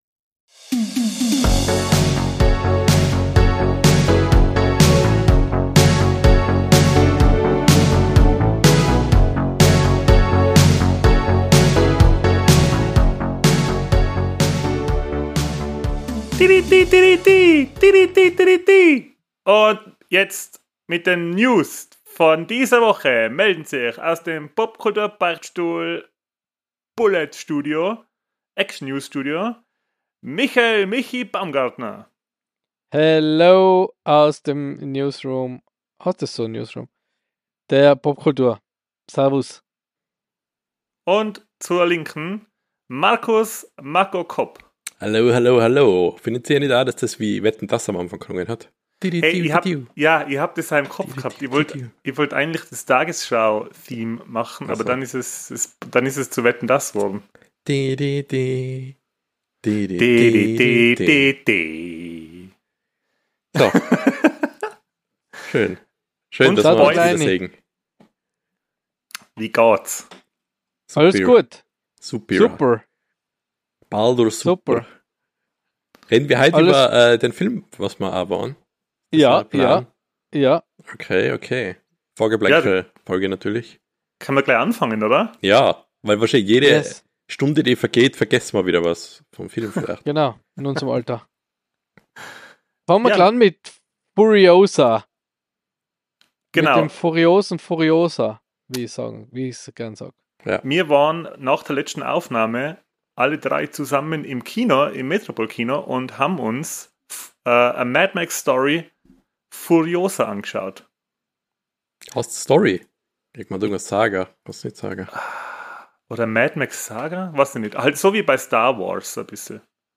Diesmal wieder zu Dritt widmen wir uns einigen Gaming Themen.